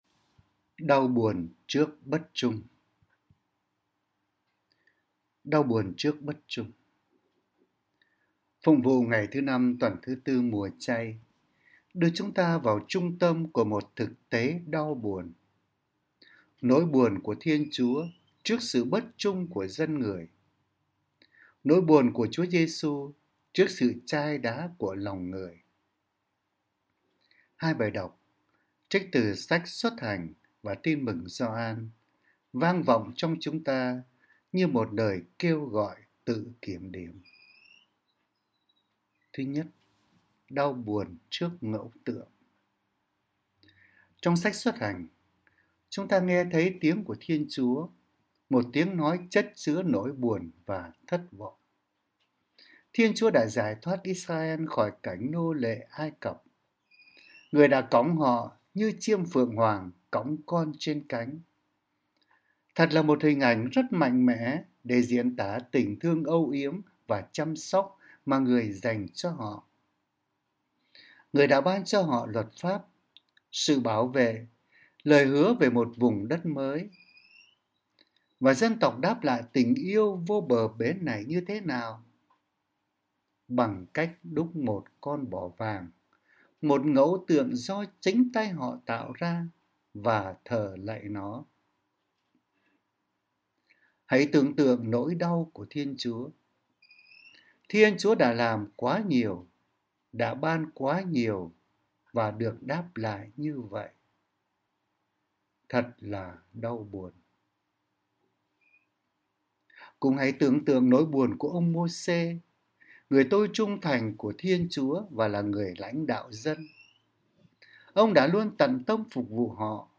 Suy niệm hằng ngày